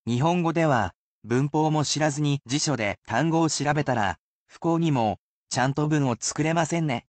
They are spoken at regular speed, so there is no need to repeat after the sentences, unless you are more advanced.